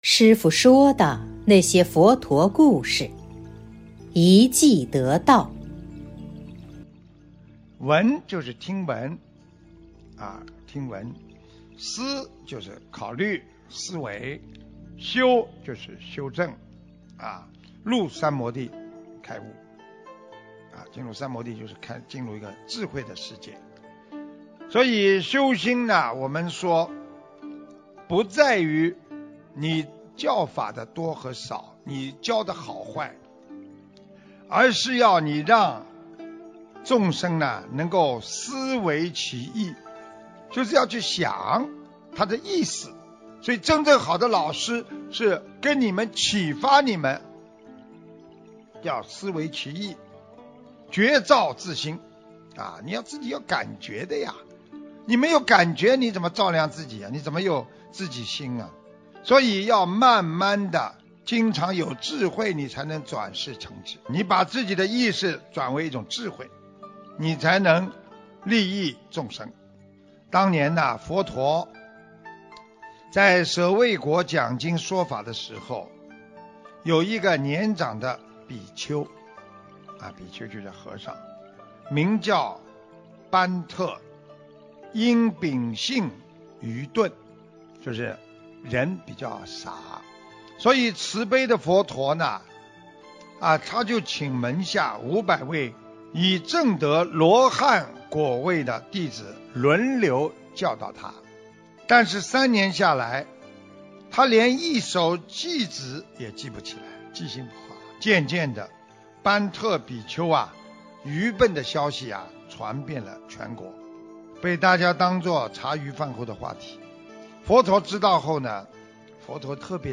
首页 >>弘法视频 >> 师父说佛陀故事
音频：《一偈得道的般特比丘》师父说的那些佛陀故事！（后附师父解说）【师父原声音】 ☞点击这里：下载 mp3 音频 【〔视频〕《一偈得道的般特比丘》师父说的那些佛陀故事！